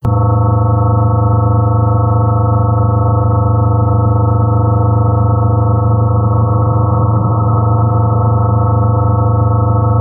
Modulation: 12 carriers DBPSK or DQPSK + pilot tone
Bandwidth: 2.7 kHz
In “Idle” mode it does not transmit data.